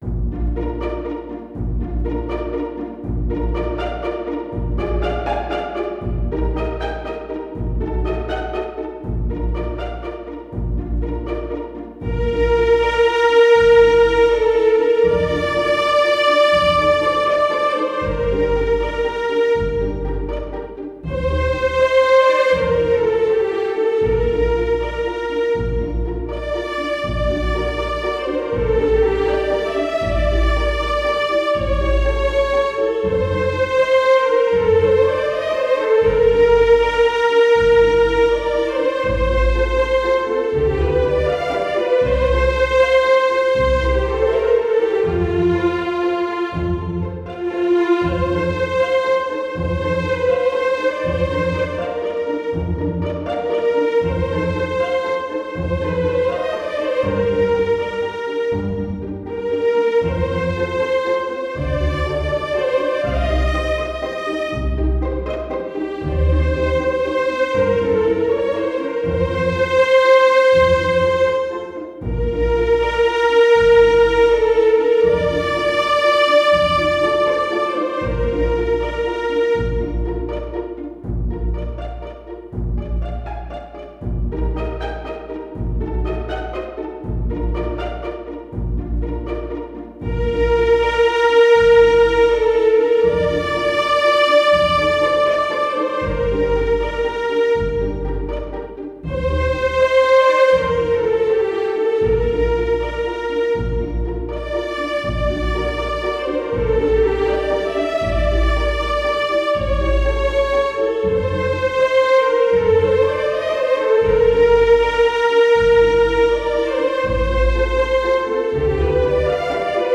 para Cordas e Voz
para Orquestra de Cordas e Voz (soprano)
● Violino I
● Violino II
● Viola
● Violoncelo
● Contrabaixo
● Voz (Soprano)